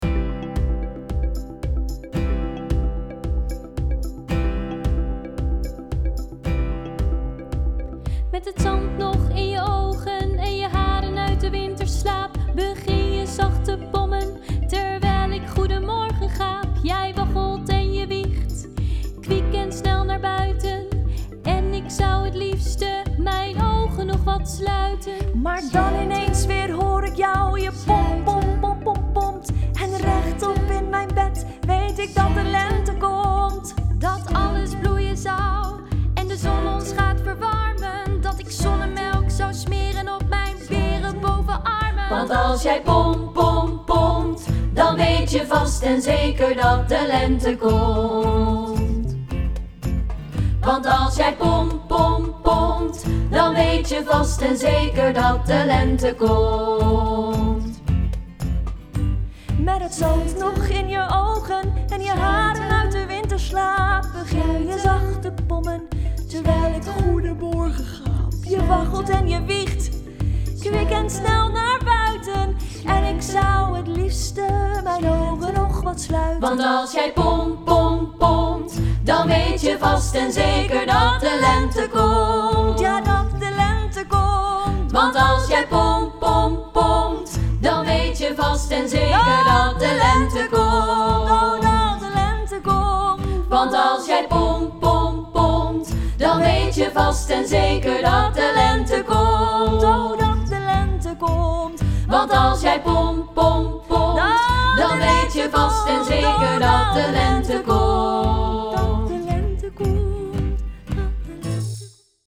pom pom pom – meezingversie | Nekka